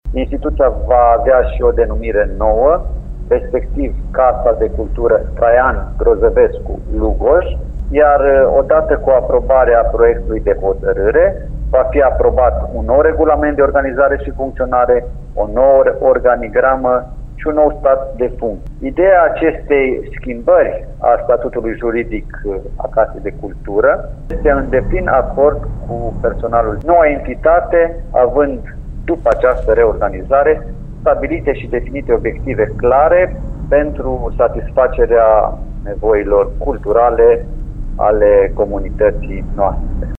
Bogdan Blidariu, primarul interimar al Lugojului.